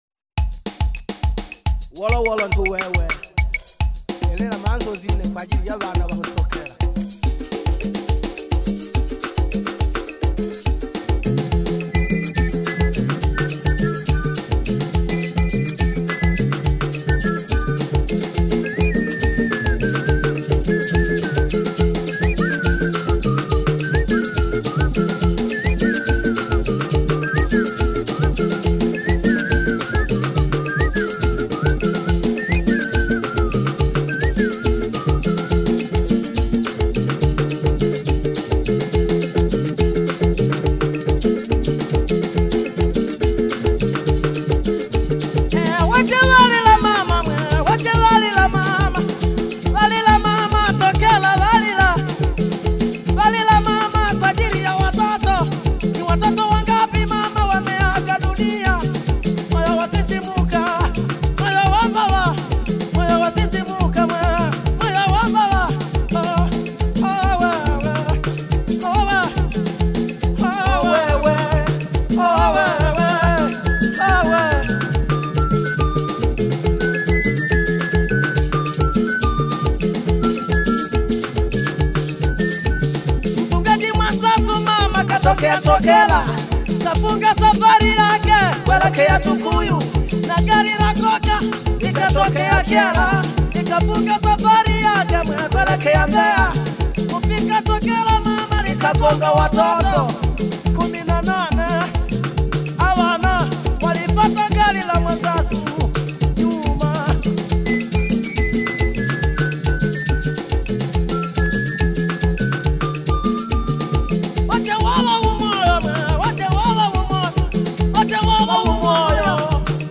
atmospheric soundscapes